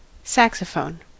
Ääntäminen
Synonyymit sax Ääntäminen US US : IPA : /ˈsæksəfoʊn/ Lyhenteet ja supistumat (musiikki) Sax.